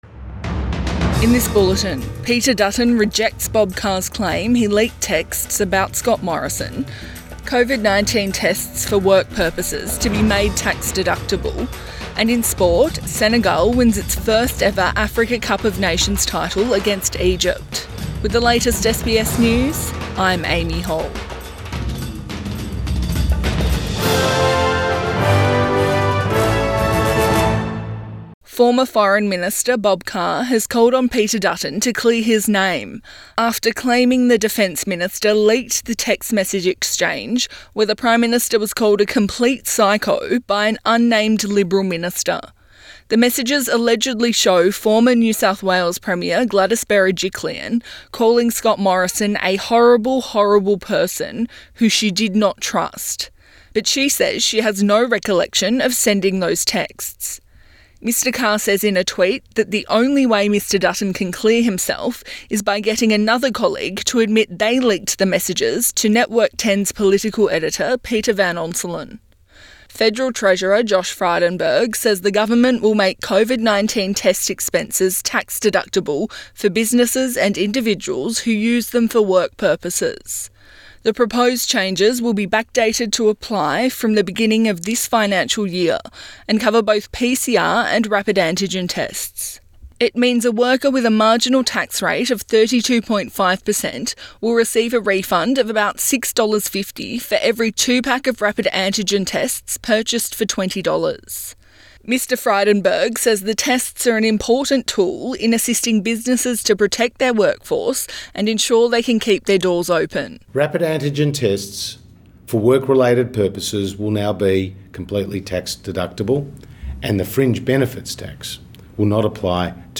Midday bulletin 7 February 2022